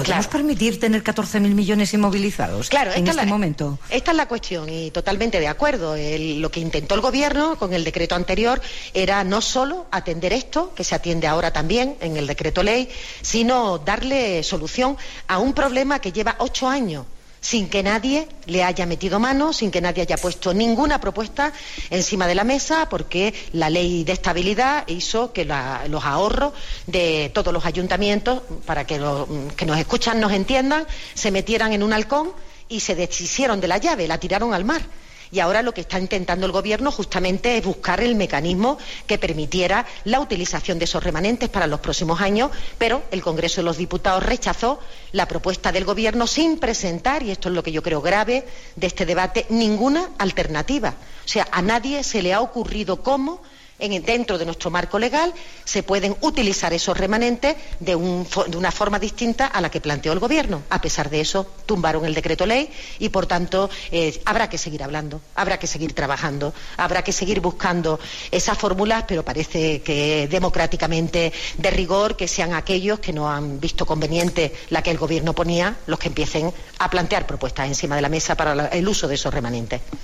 andaluz.mp3